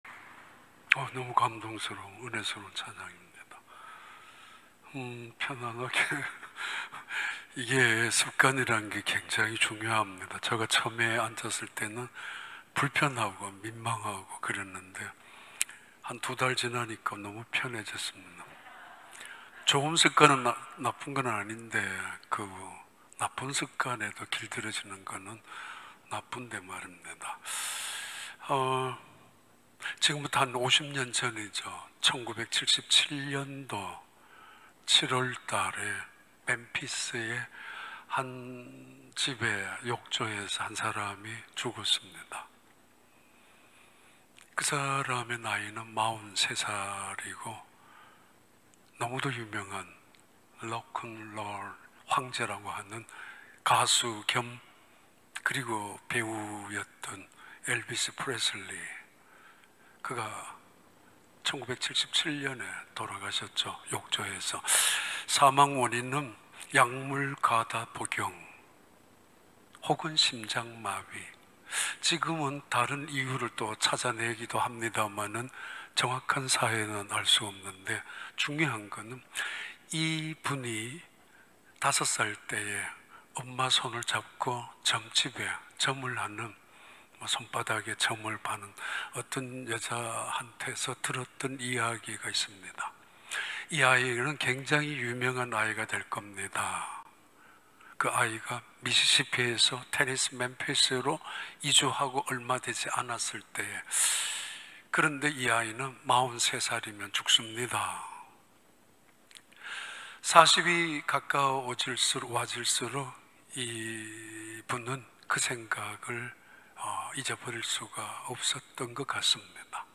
2021년 9월 19일 주일 3부 예배